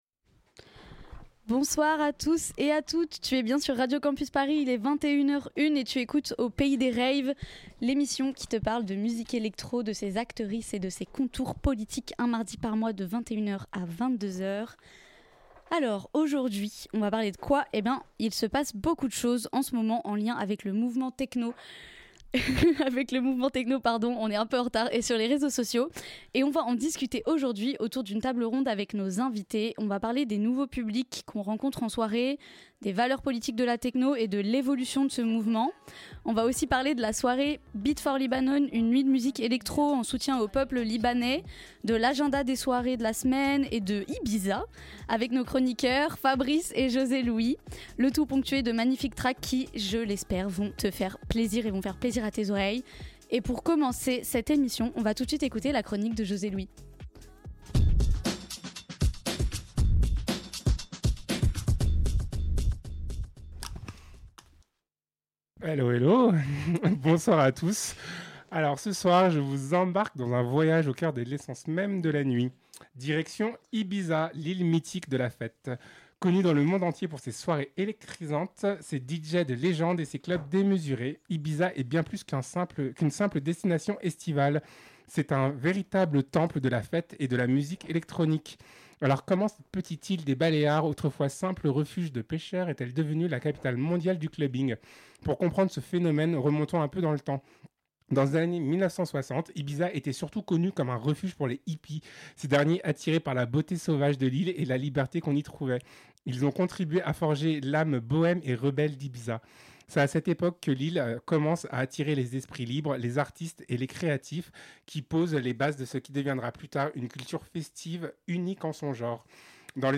Retrouvez le deuxième épisode, en direct, de l'émission Aux Pays Des Raves !